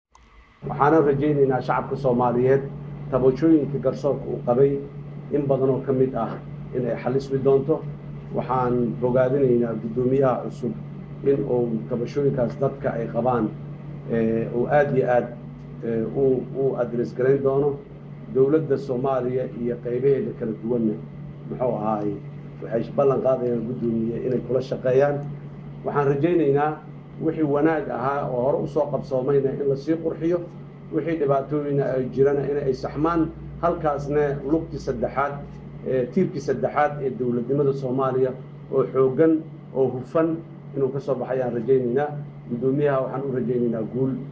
Xasan Sheekh oo u hadlay si gudoomiyihii hore ee maxkamada Ilka Xanaf uu ku fadhiyay garsoor xumo: MAQAL